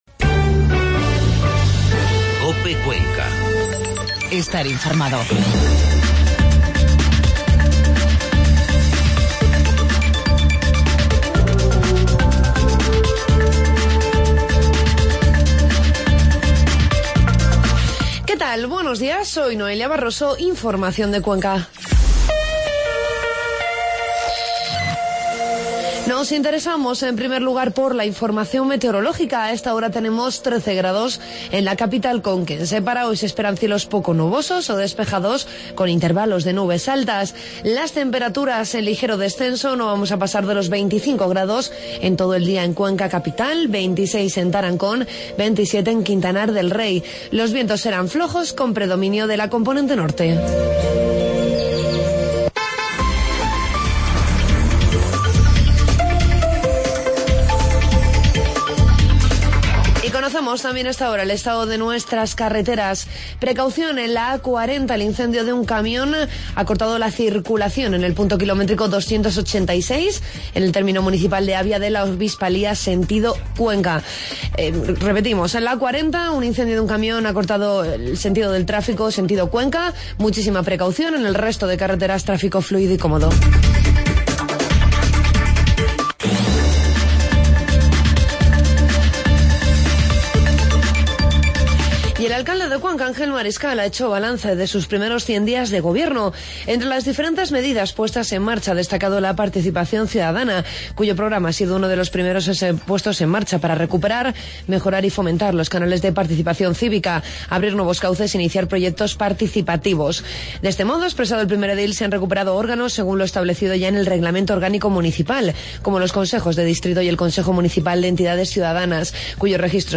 Informativo matinal Cuenca miércoles 23 de septiembre